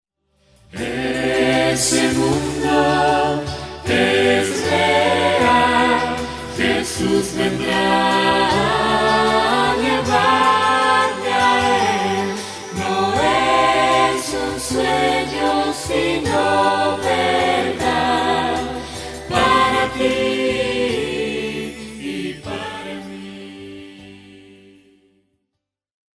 llena de adoración y reverencia